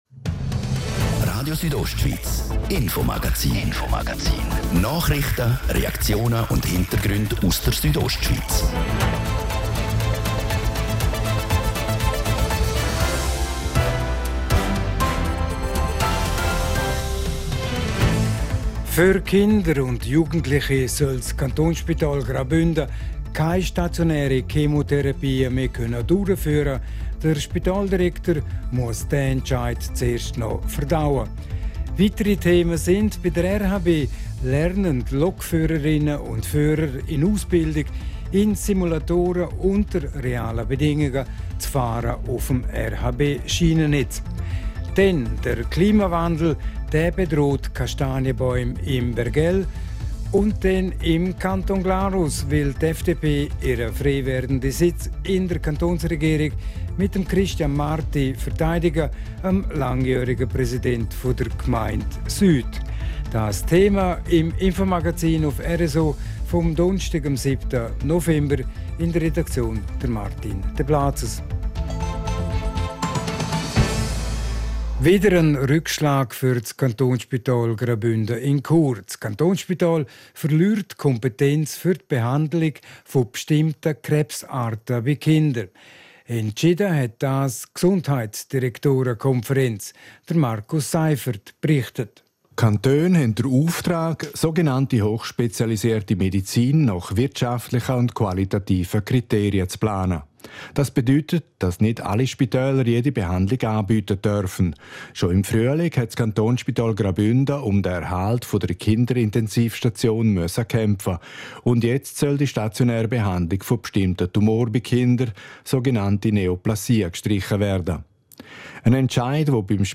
Eine Kastanien-Expertin aus dem Bergell erklärt.